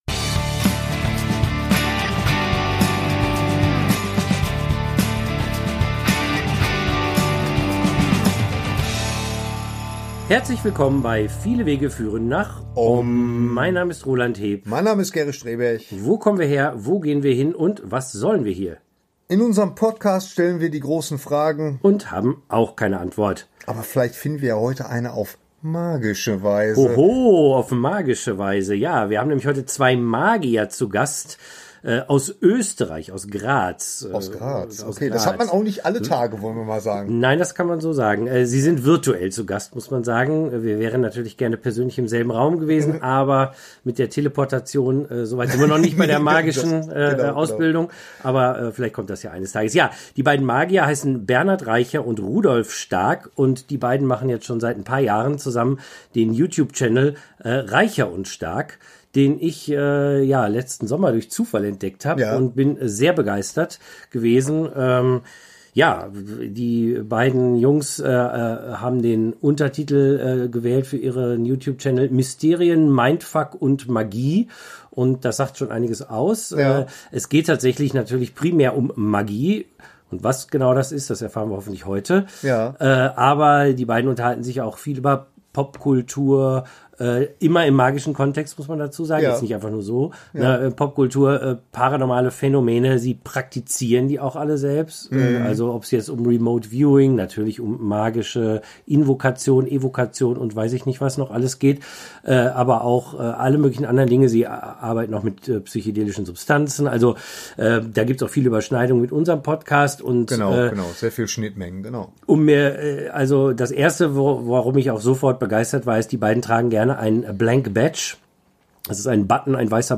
Und wie unterscheidet sich ihre Magie von der, die wir aus dem Kino oder aus Büchern kennen? All das erfahrt ihr in diesem äußerst spannendem und magischem Gespräch.